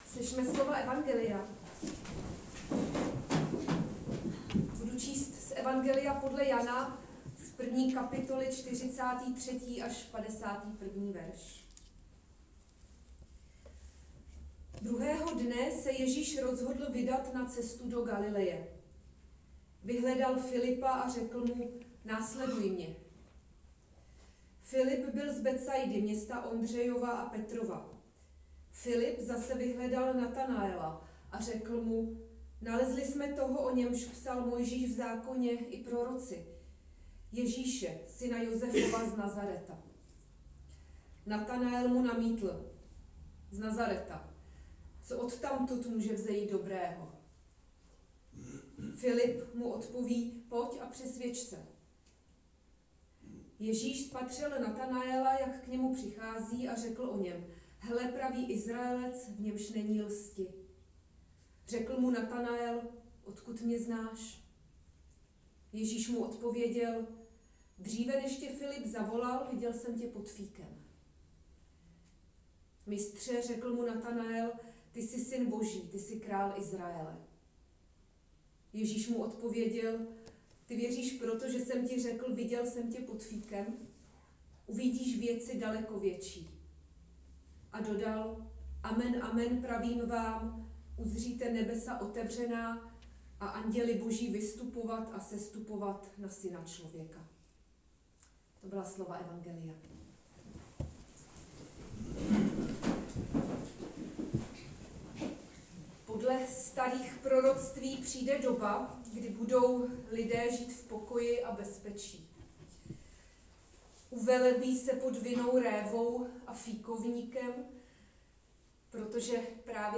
audiokázání: Jan 1, 43-51